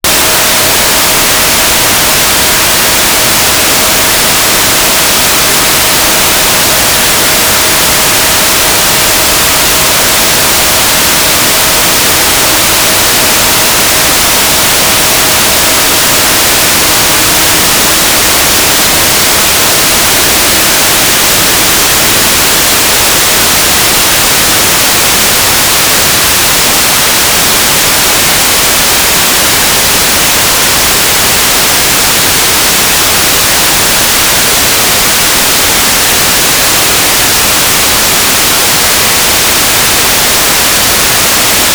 "transmitter_description": "Telemetry",
"transmitter_mode": "FM",